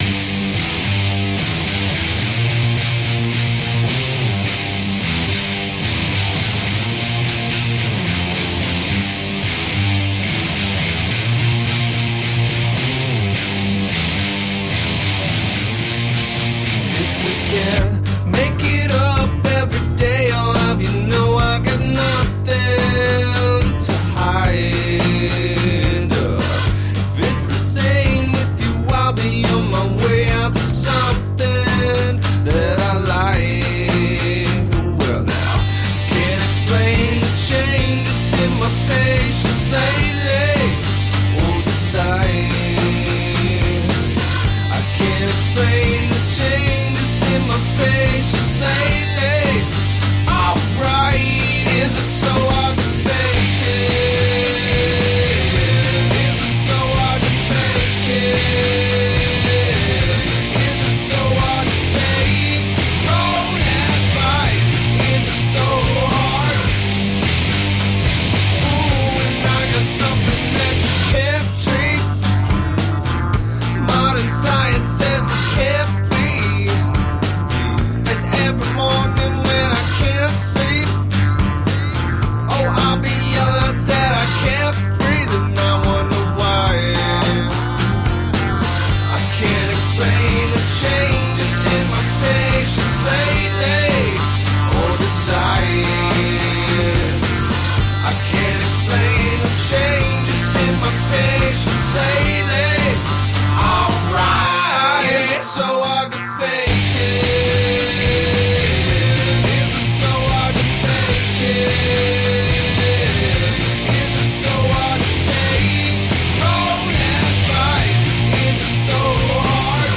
Brilliantly sardonic indie rock.
It's a pop/rock record about choices and change.
Tagged as: Alt Rock, Other, Indie Rock, Ironic Rock